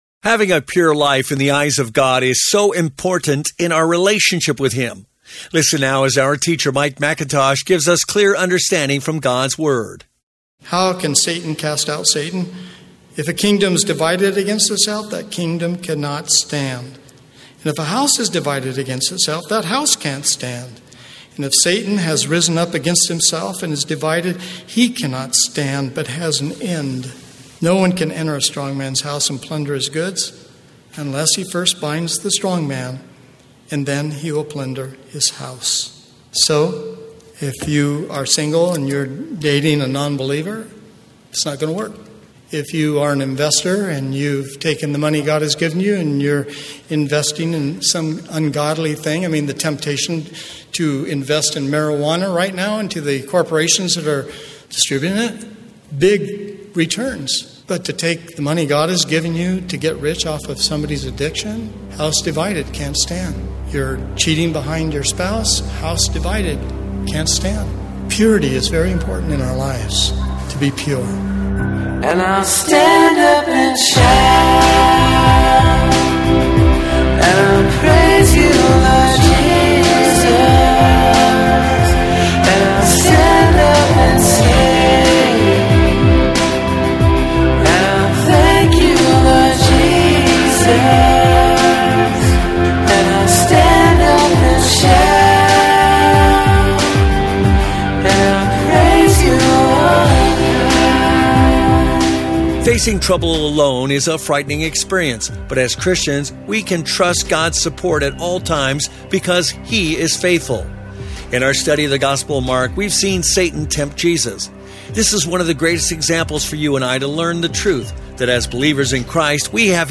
podcasts live from Café Anyway in podCastro Valley